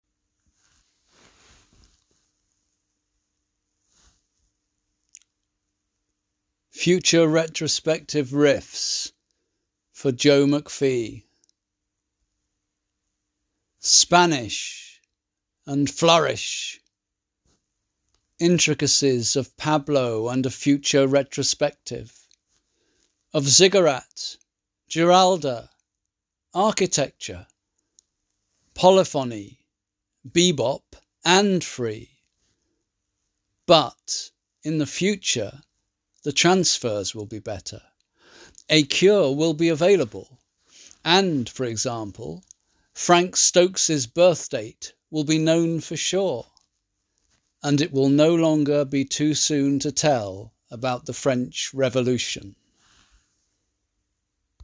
The Sunday Poem  is published weekly, and strives to include the poet reading their work.